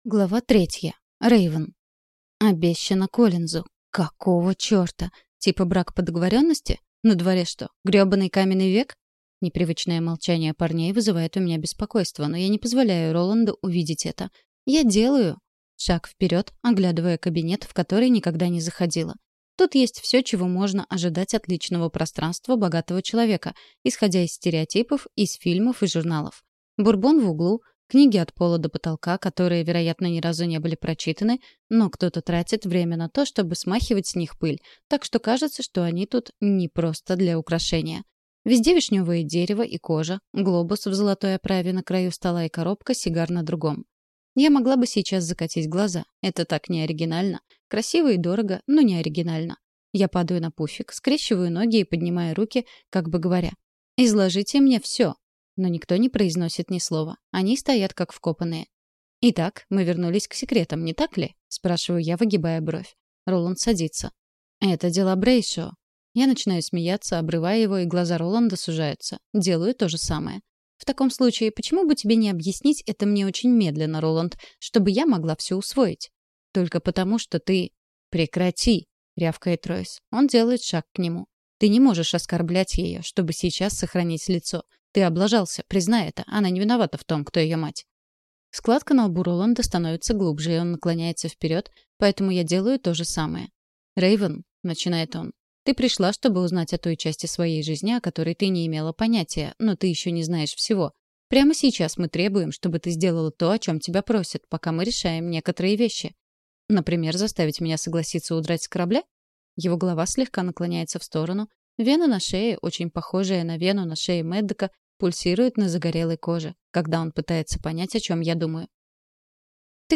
Аудиокнига Короли старшей школы | Библиотека аудиокниг